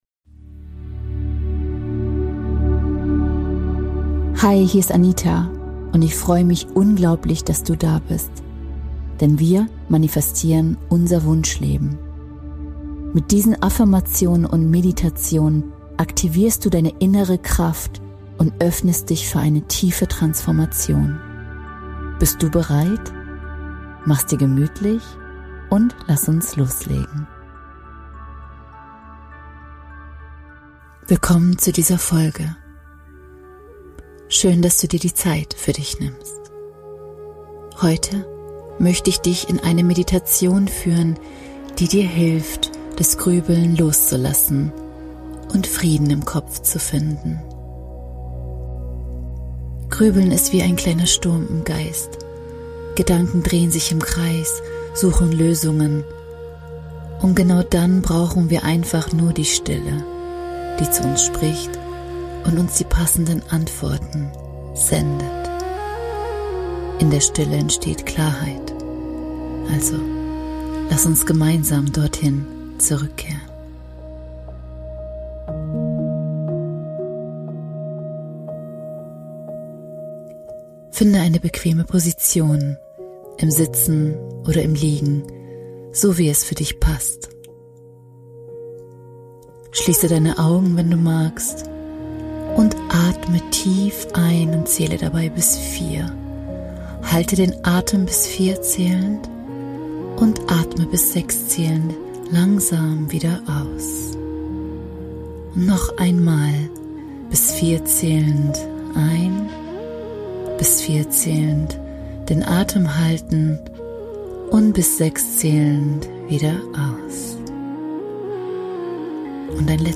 Diese geführte Meditation hilft